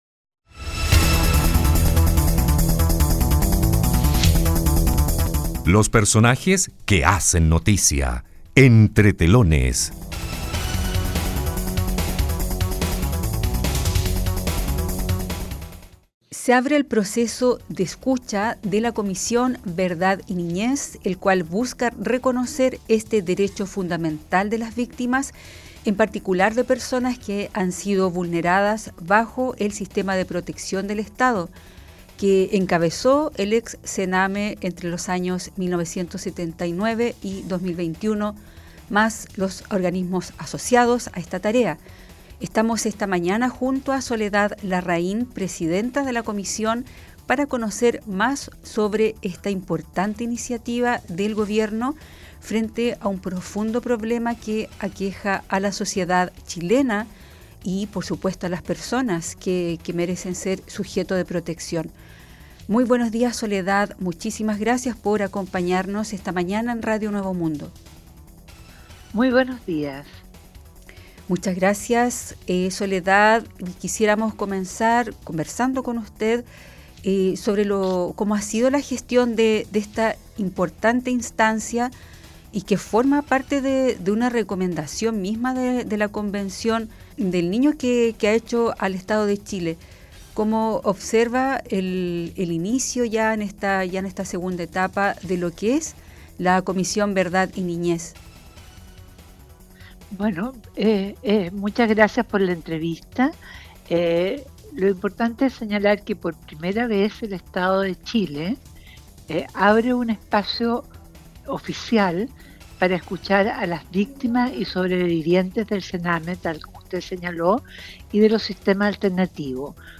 [Podcast] Presidenta de la Comisión Verdad y Niñez detalla proceso de escucha para víctimas del ex Sename